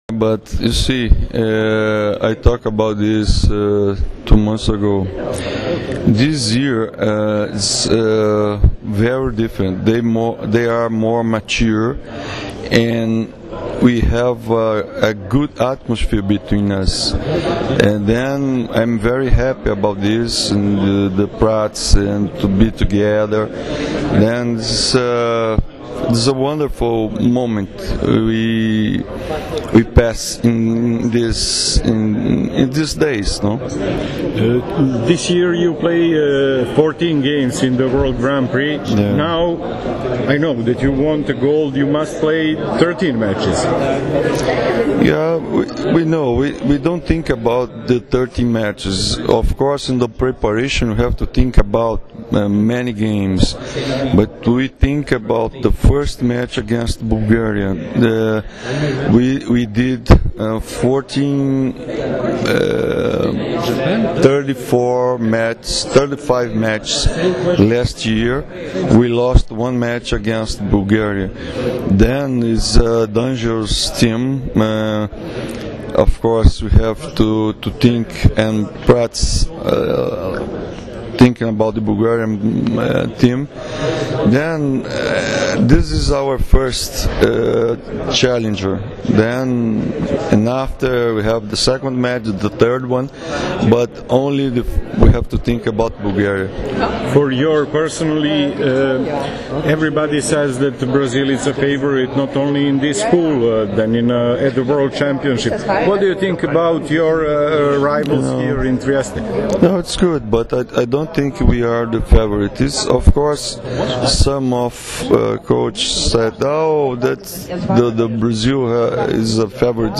IZJAVA ŽOZEA ROBERTA GIMARAEŠA ZE ROBERTA